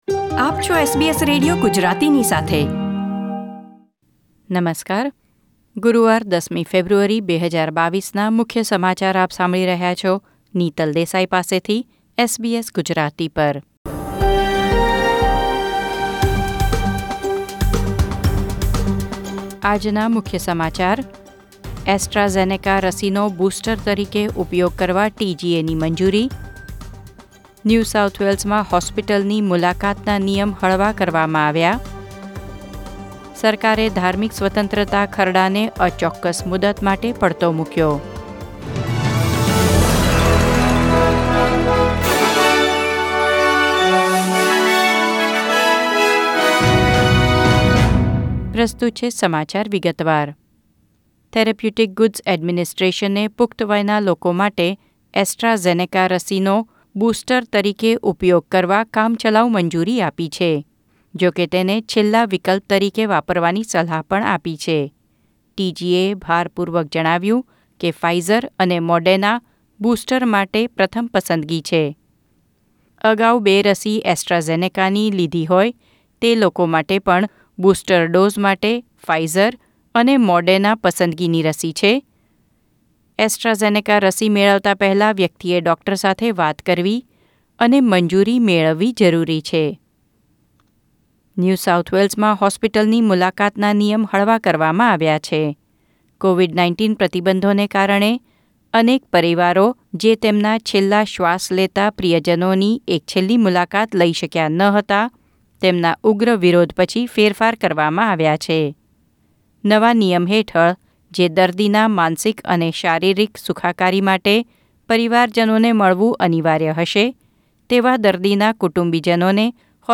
SBS Gujarati News Bulletin 10 February 2022